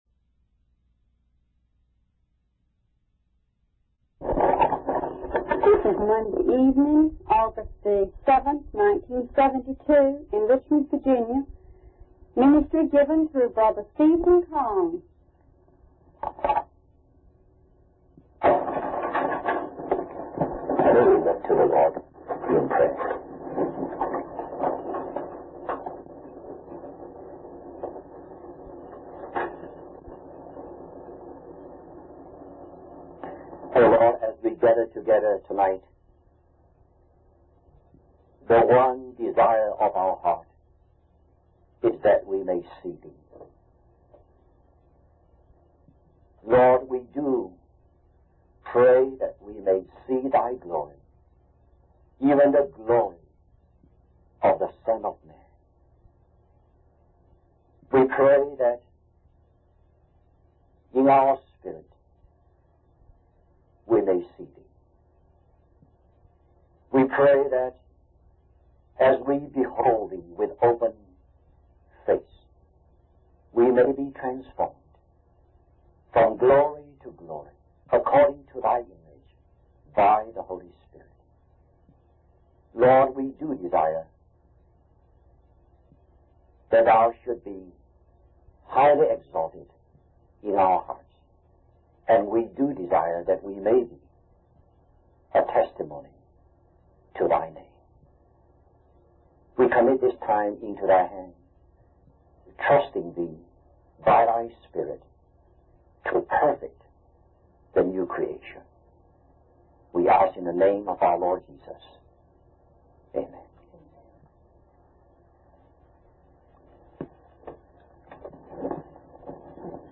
In this sermon, the preacher discusses the characteristics of a 'big man' or a true follower of Christ. He emphasizes that the more one is criticized, the more they should love others, just as Jesus did. The preacher also highlights the importance of being humble and having a single-minded focus on God's work, just like Jesus demonstrated throughout his life.